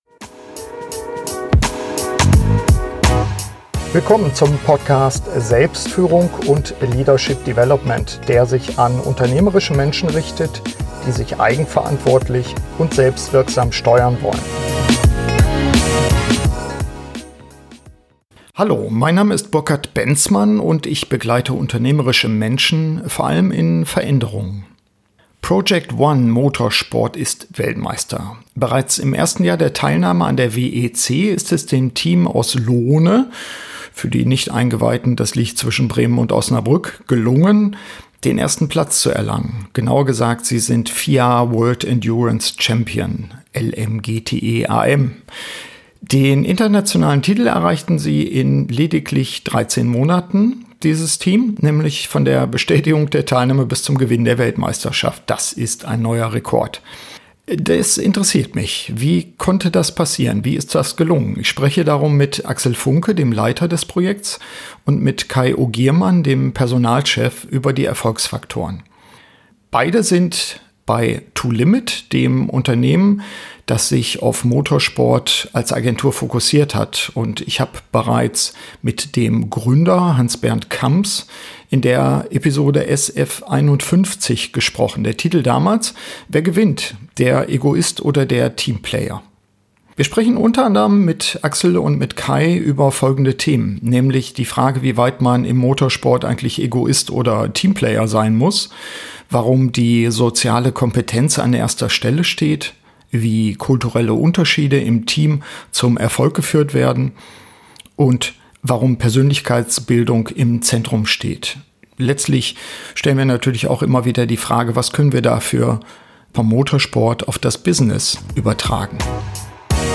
SF117 Wie wird man Weltmeister? Interview